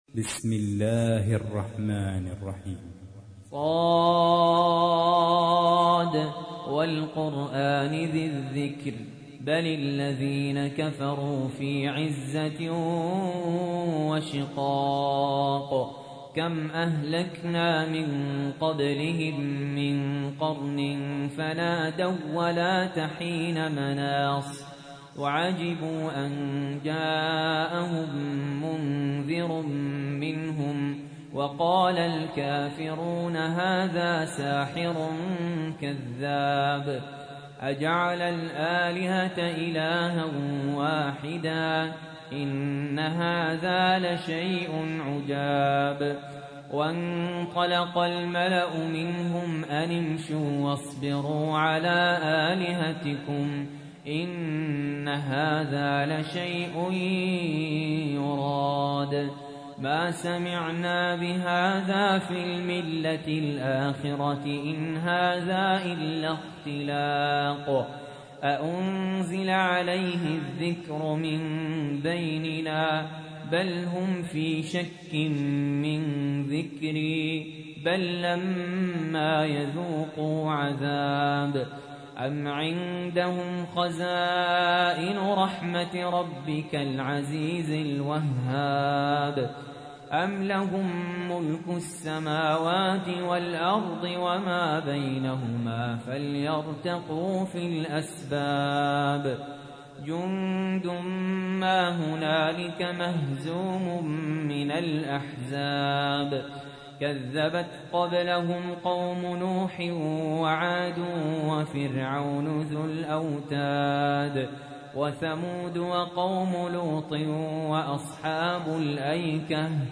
تحميل : 38. سورة ص / القارئ سهل ياسين / القرآن الكريم / موقع يا حسين